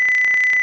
作为测试、我将从我的 iPhone 生成一个2kHz 正弦波、并通过其中一个通道进行收听。 生成的 WAV 文件已附加-我不清楚为什么它听起来像这样。